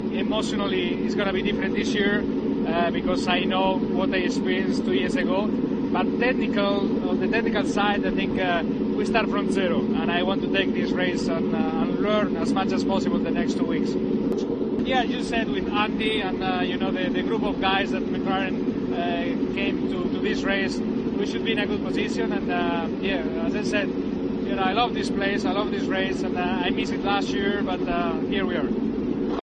El asturiano habló con la web oficial de Indy Car antes de subirse a su McLaren Chevrolet en los primeros test en Indianápolis.